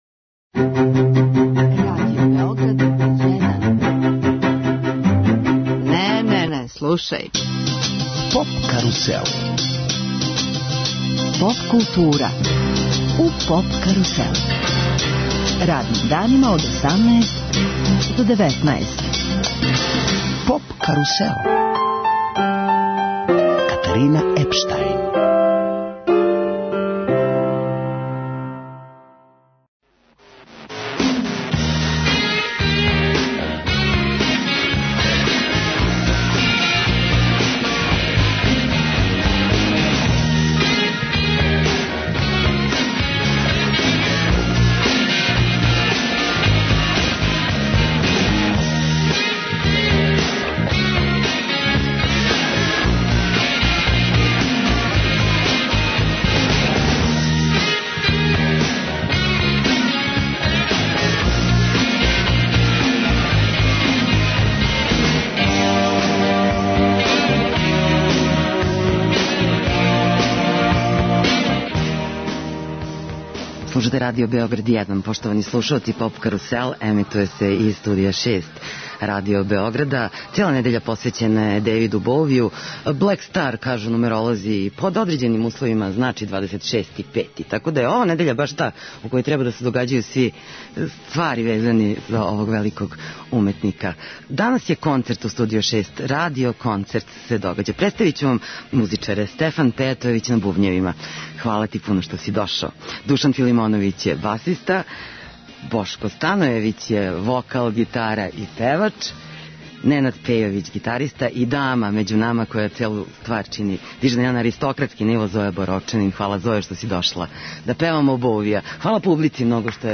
Емитујемо радио документарни програм посвећен Елвису Преслију.